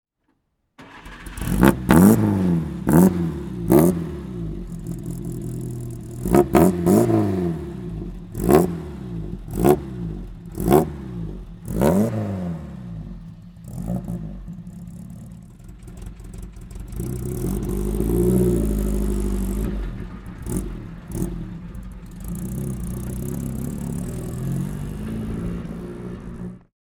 Fiat 508 C 1100 Sport CarGem (1937/1946) - Starten und Leerlauf